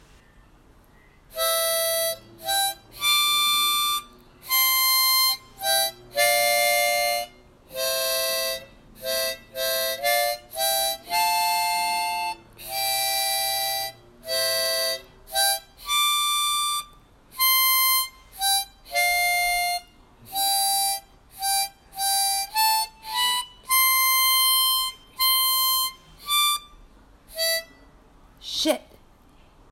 My harmonica man